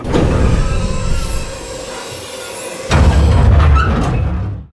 bayopen.ogg